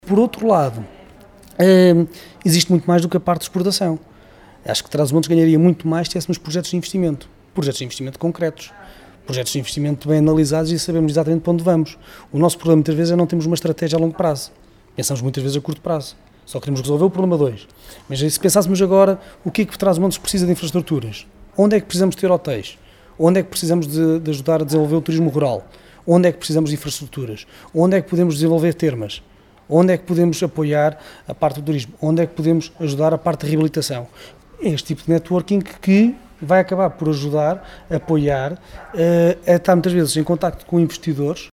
Declarações à margem da sessão de esclarecimento “Macau como Plataforma dos 3 Centros – Apoio à exportação para o mercado chinês”, que ontem decorreu em Macedo de Cavaleiros.